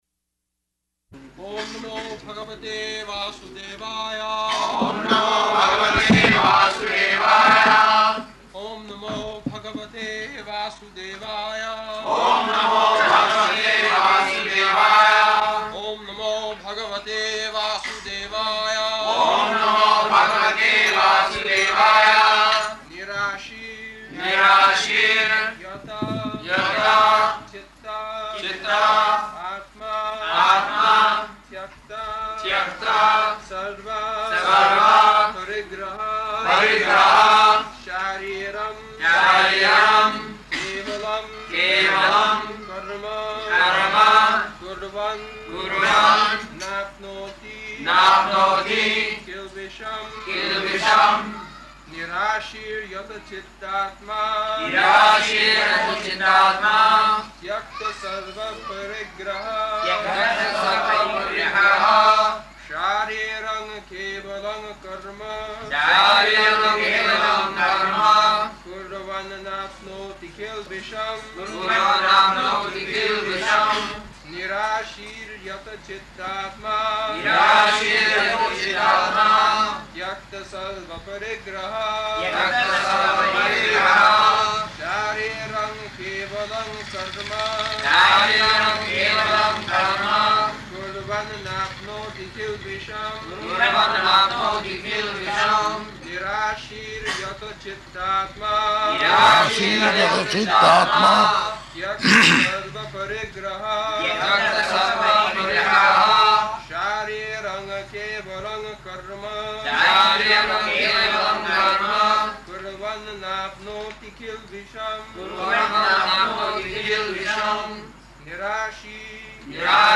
April 10th 1974 Location: Bombay Audio file
[Prabhupāda and devotees repeat]